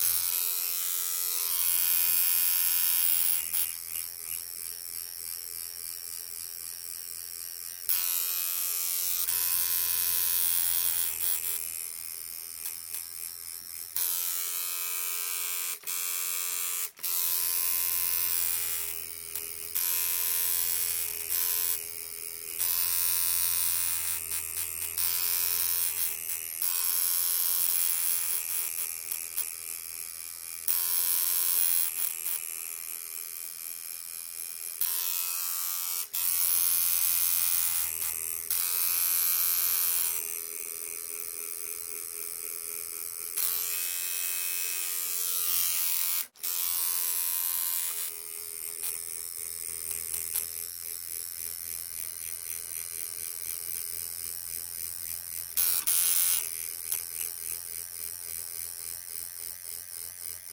На этой странице собраны звуки работы тату-машинки в разных режимах: от мягкого жужжания до интенсивного гудения.
Шум работающей тату машинки